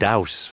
Prononciation du mot douse en anglais (fichier audio)
Prononciation du mot : douse